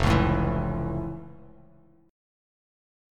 F+M7 chord